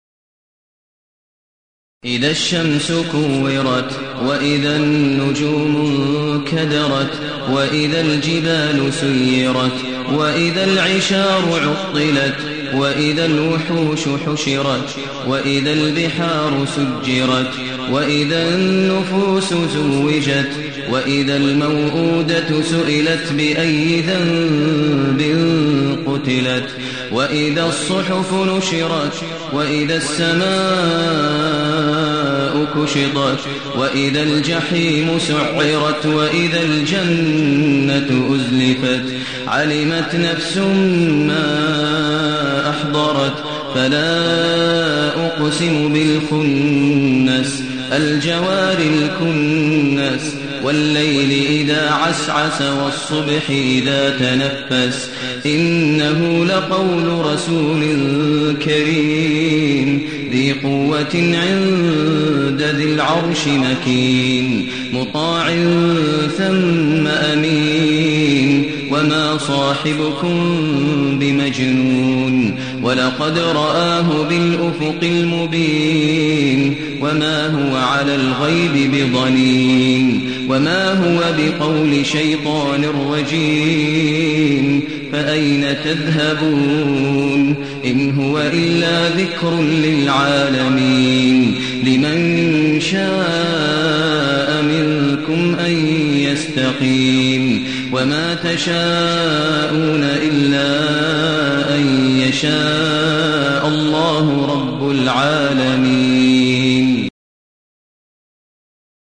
المكان: المسجد الحرام الشيخ: فضيلة الشيخ ماهر المعيقلي فضيلة الشيخ ماهر المعيقلي التكوير